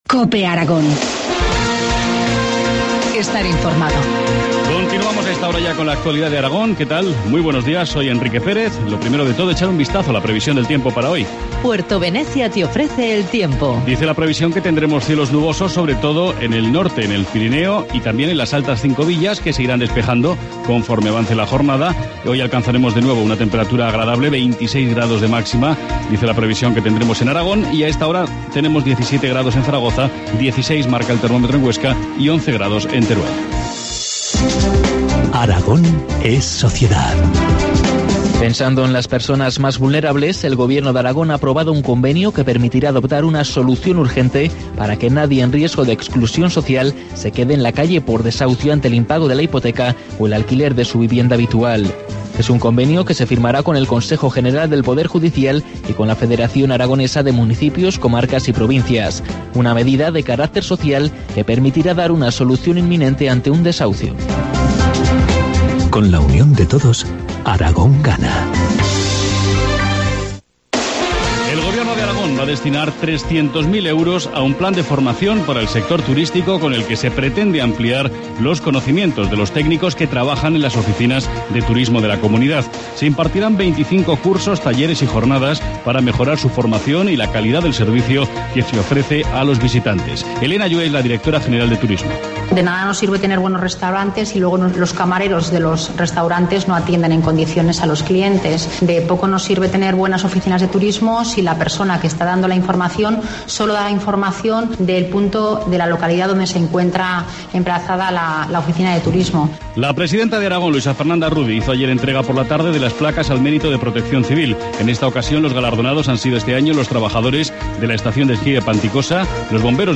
Informativo matinal, martes 7 de mayo, 8.25 horas